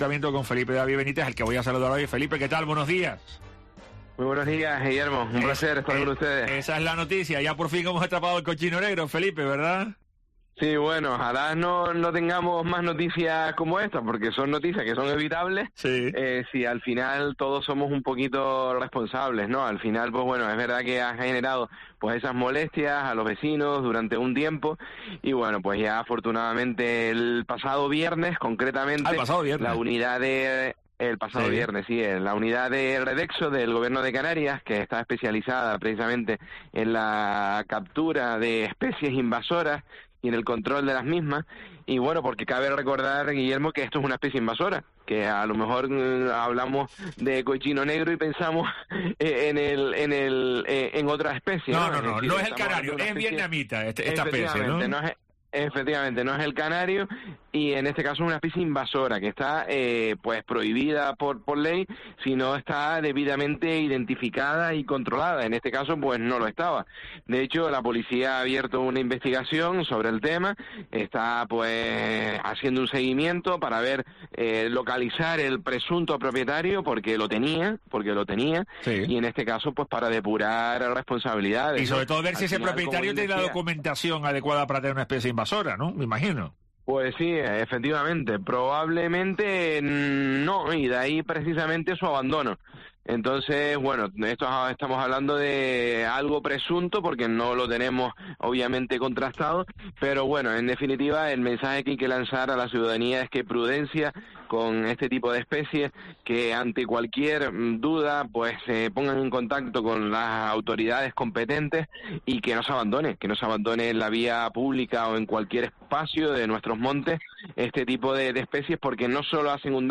A partir de ahí, el cerdo ha estado monitorizado en todo momento por un equipo especializado de esta consejería, ya que todo el proceso de su captura, tenía que realizarse de acuerdo a la normativa de protección animal, tal y como ha destacado en Herrera en COPE Tenerife el portavoz del Ayuntamiento de La Orotava, Felipe David Benítez.